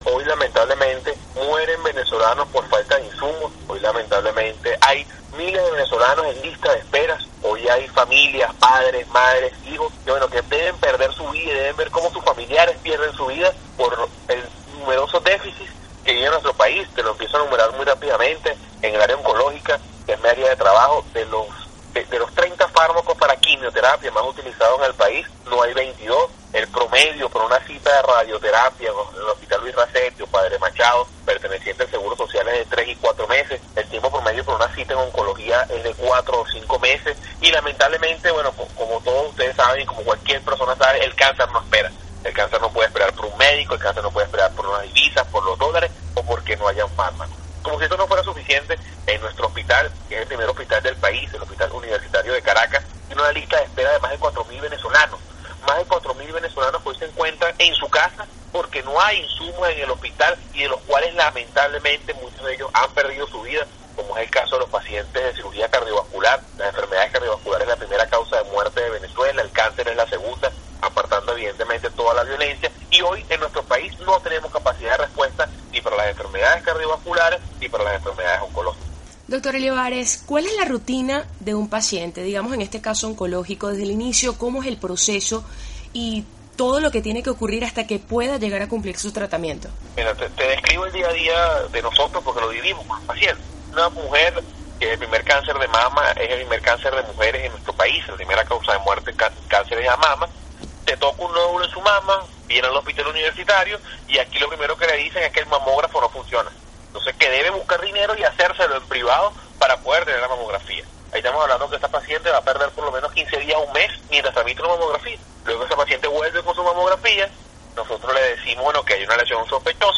Entrevista al médico venezolano José Manuel Olivares